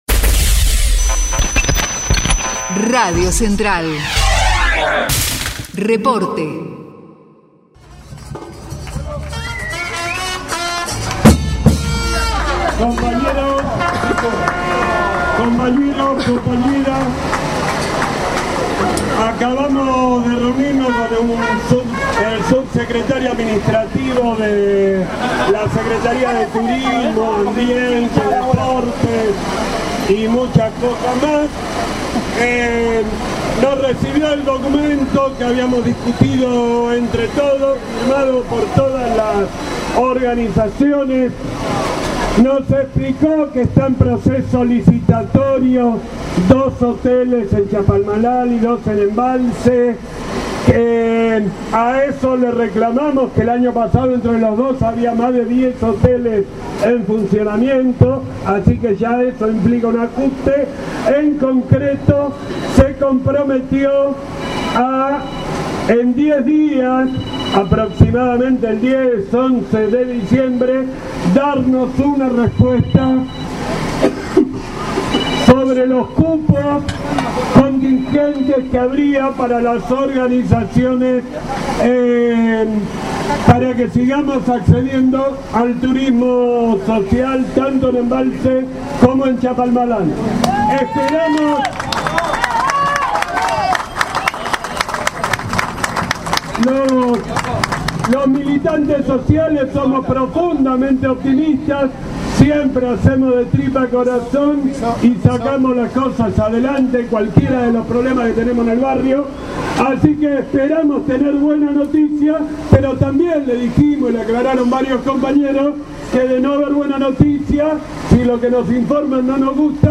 PILETAZO EN DEFENSA DEL TURISMO SOCIAL - TESTIMONIOS CTA
piletazo_en_defensa_eel_turismo_social_-_testimonios_cta.mp3